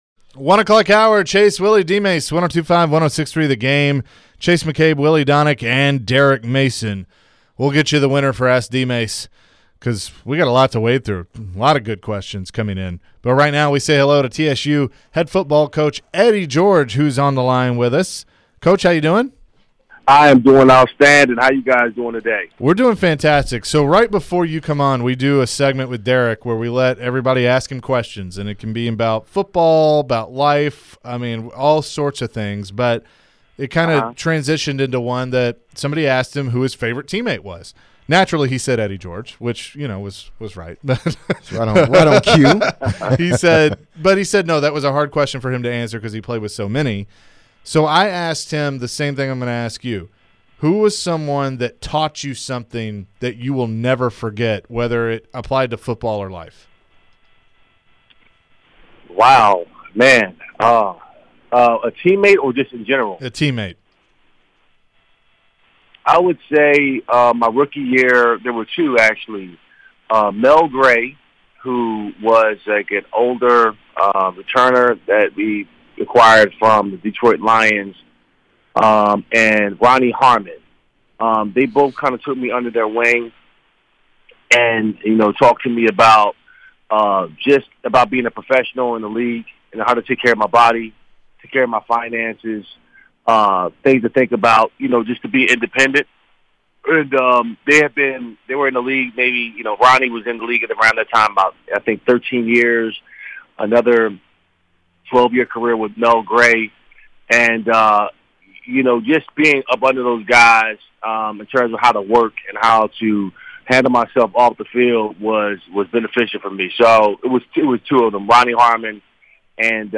Eddie George interview (10-21-22)